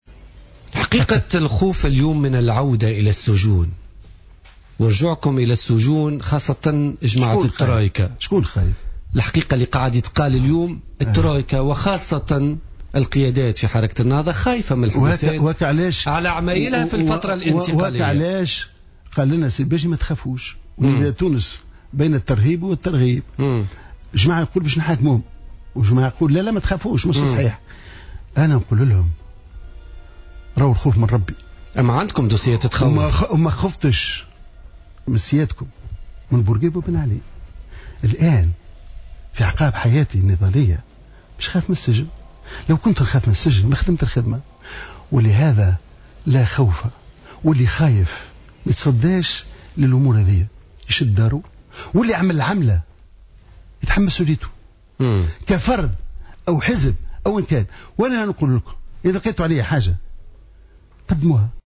Hamadi Jebali ancien leader du mouvement Ennahdha est revenu ce mercredi 17 décembre 2014 dans une intervention sur les ondes de Jawhara FM dans le cadre de l’émission Politica, sur les discours de certains leaders de Nidaa Tounes.